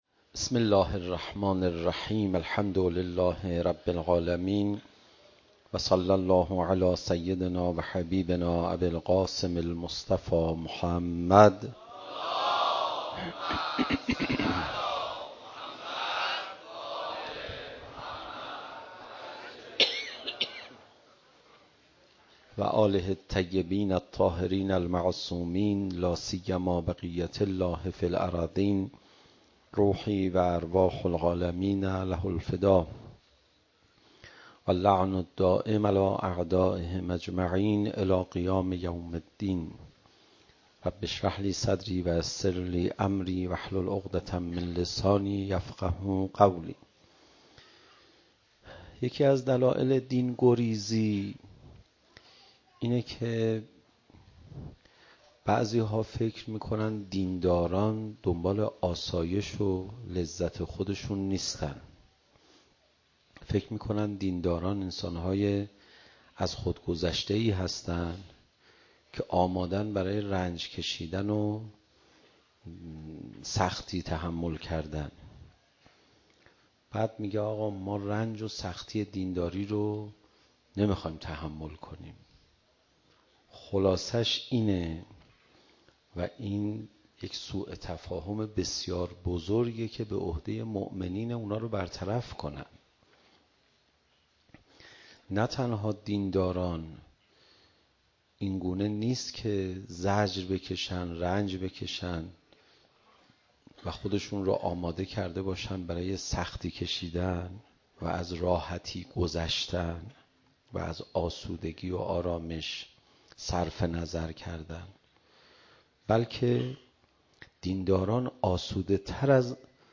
شب نهم محرم 96 - حسینیه حق شناس